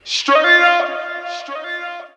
Tags: hip hop